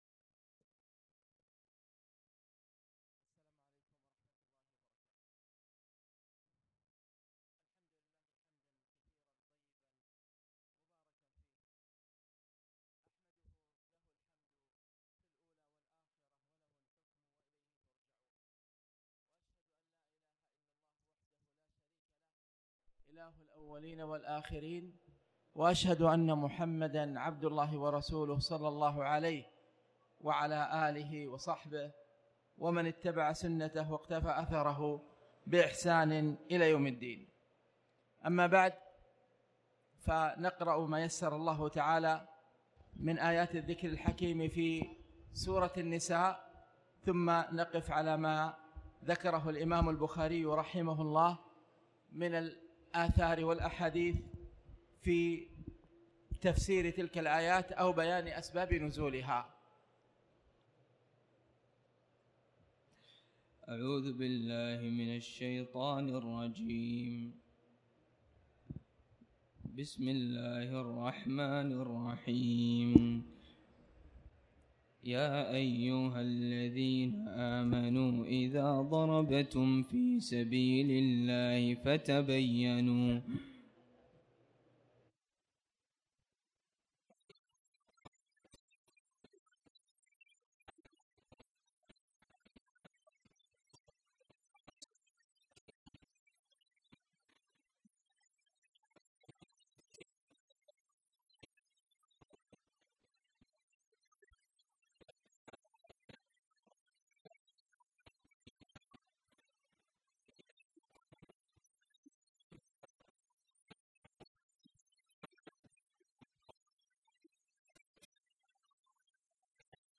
تاريخ النشر ١١ رمضان ١٤٣٩ هـ المكان: المسجد الحرام الشيخ